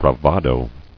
[bra·va·do]